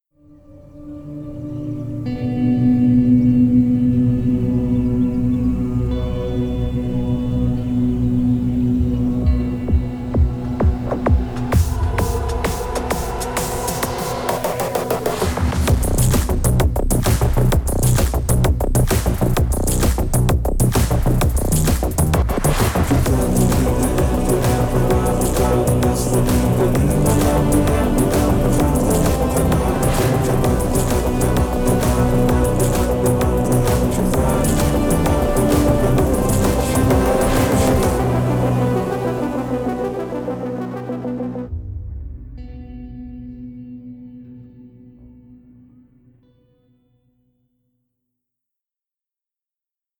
without disturbing sounds and dialogues.